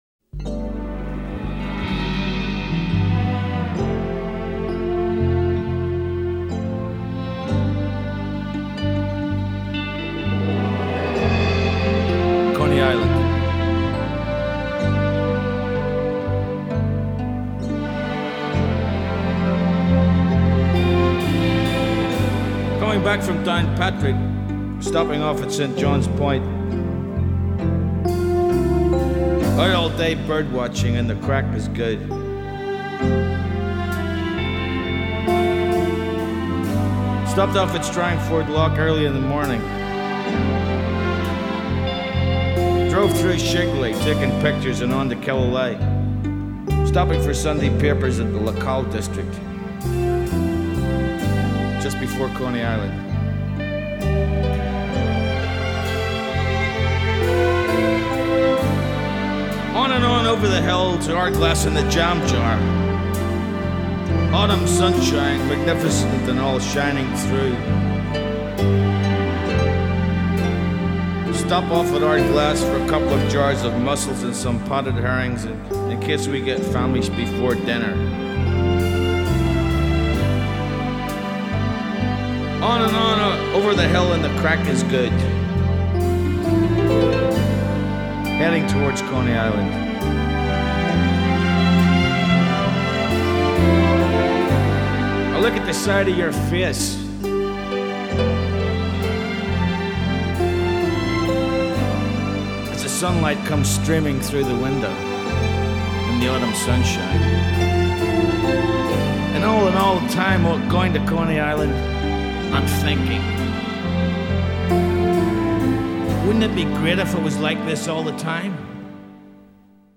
Luscious and languorous